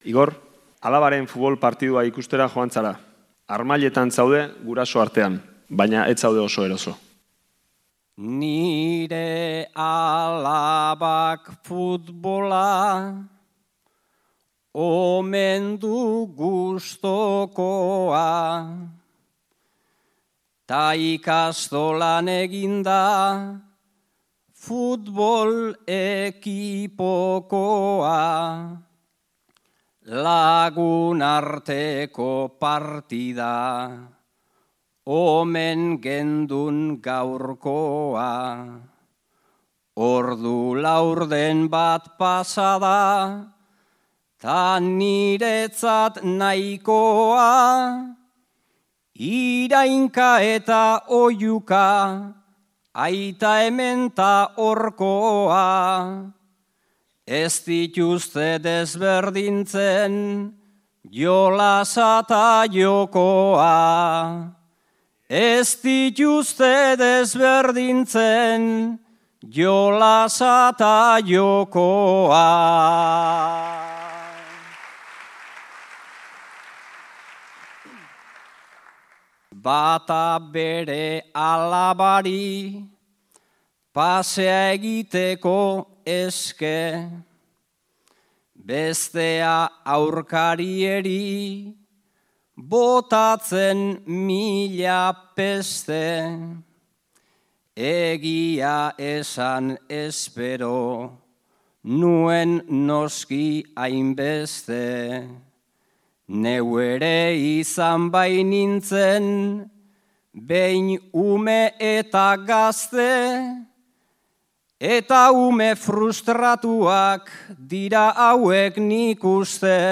Donostiako Bertso Derbian